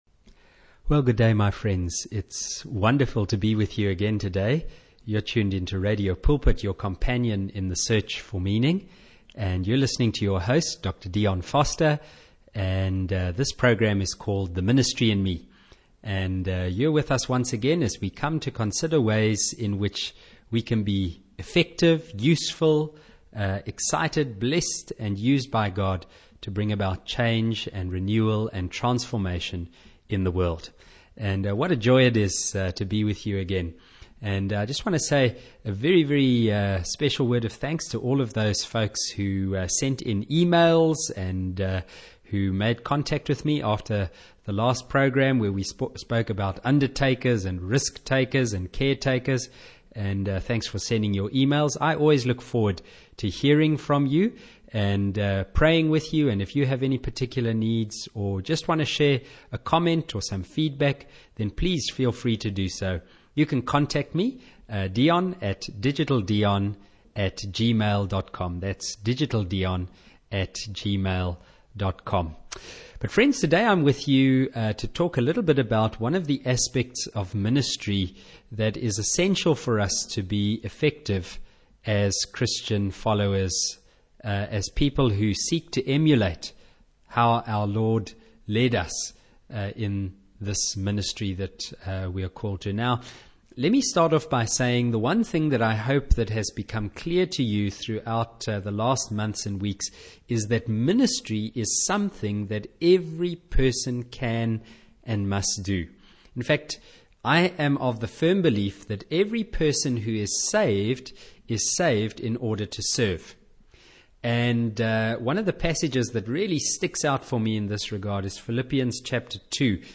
Well, here's a little message (a sermon of sorts) that I prepared for my Radio Pulpit broadcast . You can download the program here ' Jesus secret for blessing... servanthood ' (6.5MB) Please feel free to share it with anyone who may benefit from it!